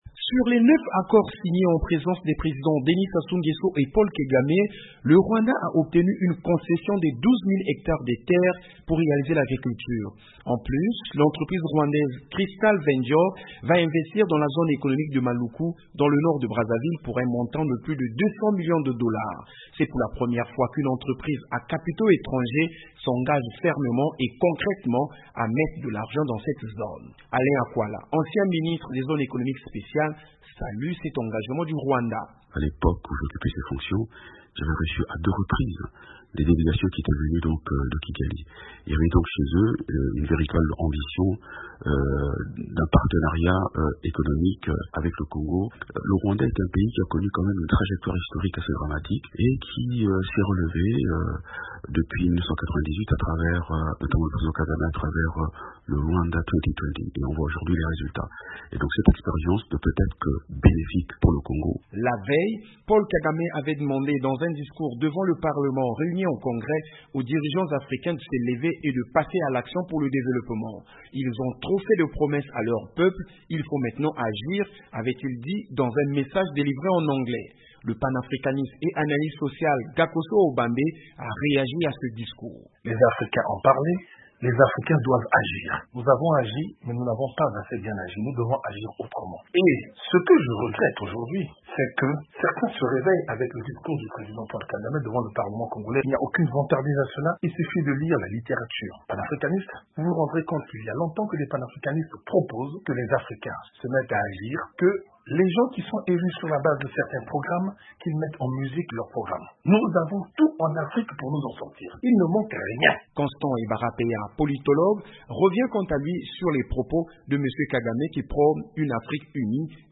quelques réactions recueillies à Brazzaville